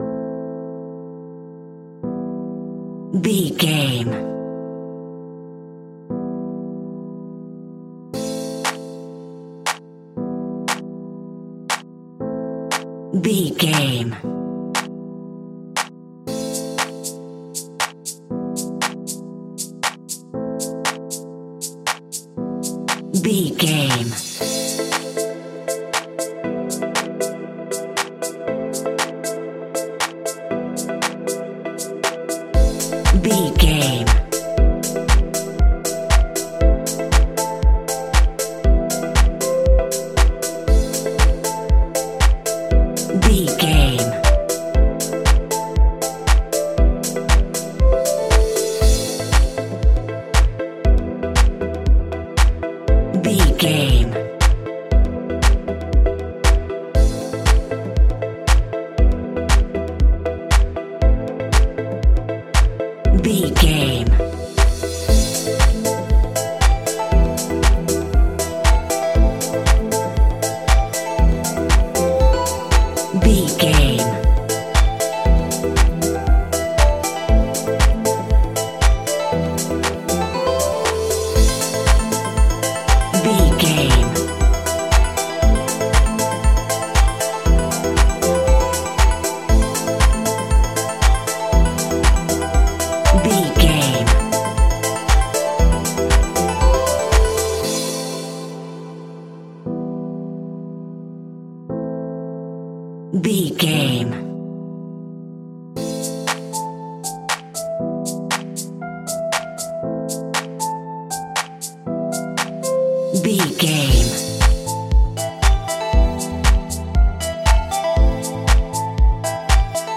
Aeolian/Minor
dark
futuristic
epic
groovy
drums
drum machine
synthesiser
electric piano
house
electro house
synth leads
synth bass